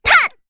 Rehartna-Vox_Attack4_kr.wav